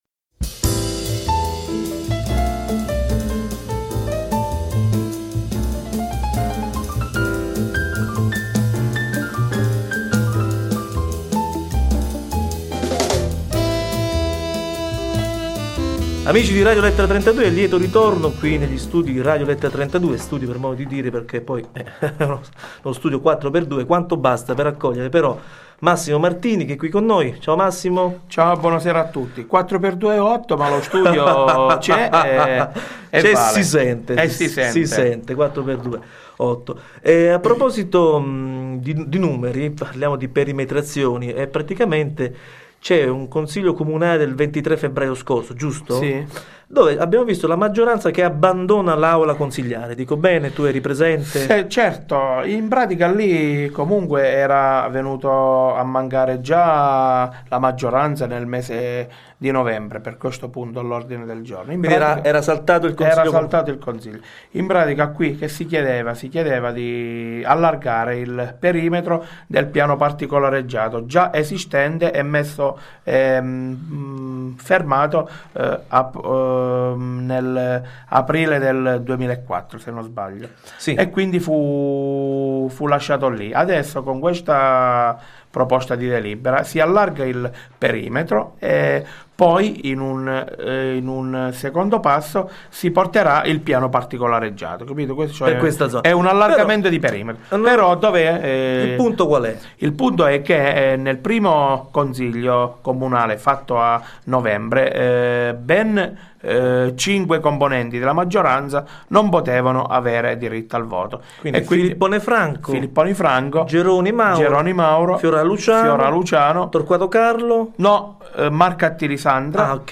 Con lui parliamo della "fuga" della maggioranza dal consiglio comunale e della tanto chiacchierata perimetrazione. L'intervista è piuttosto chiara: penso che i cittadini, ascoltandola, possano farsi un' idea più o meno precisa in merito.